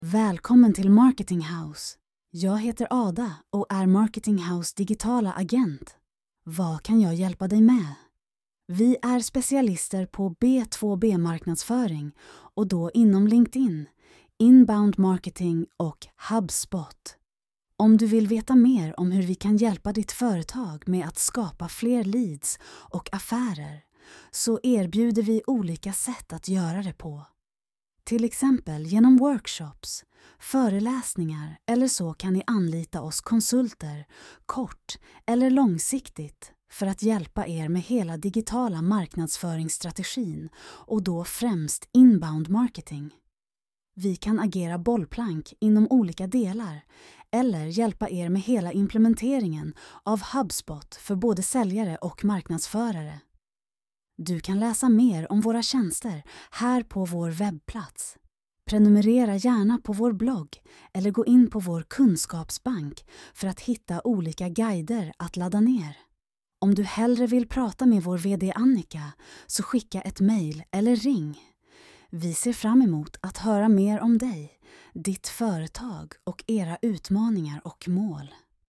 Lyssna på Ada - vår Digitala Agent
Hon berättar om vad vi kan hjälpa dig och ditt företag med
Ada Marketinghouse Digitala Agent..mp3